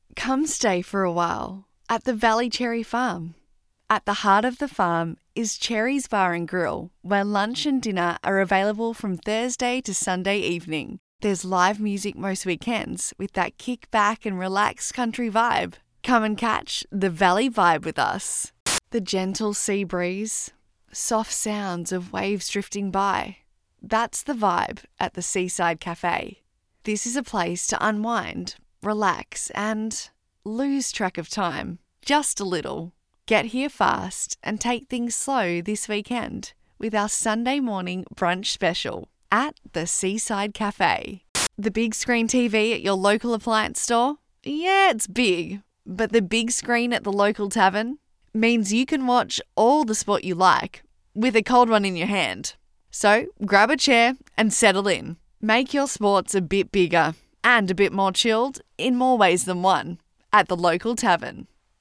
• Relaxed
• Young
• Natural